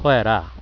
岐阜県東濃地方の方言
東濃弁を聞いてみよう